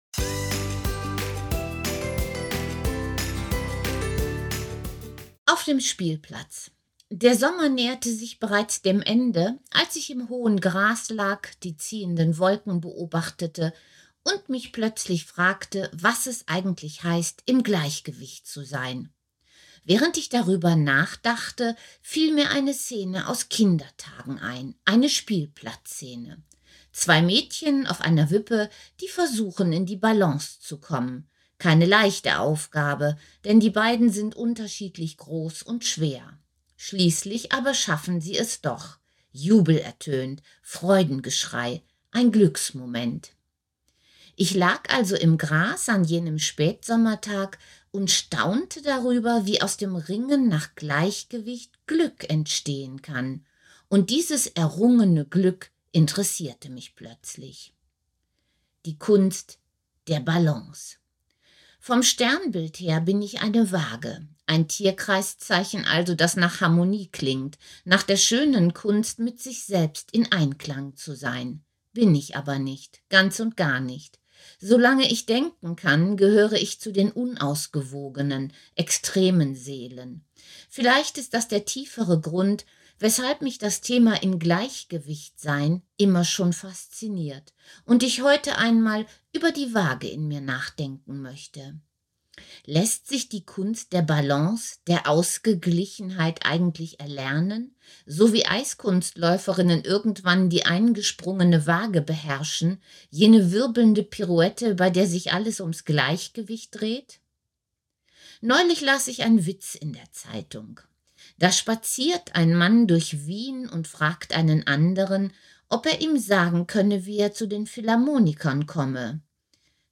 Text als Audiodatei